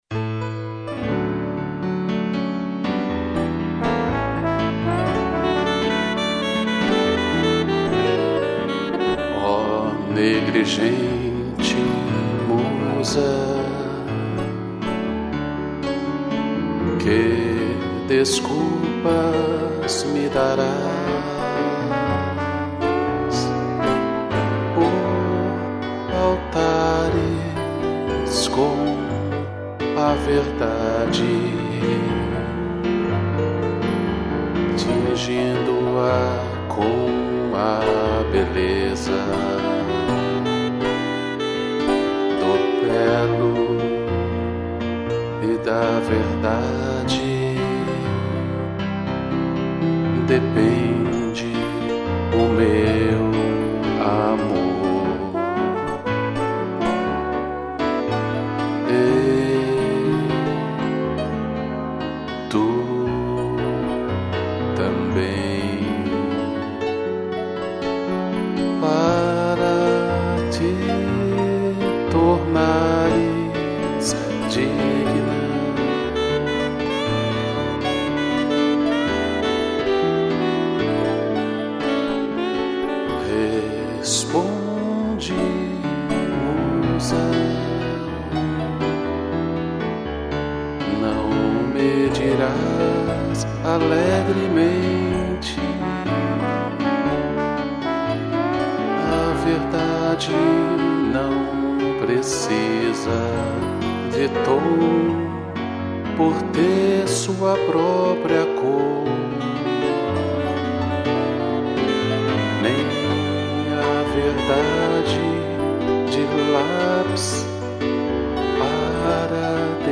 2 pianos, sax e trombone